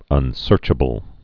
(ŭn-sûrchə-bəl)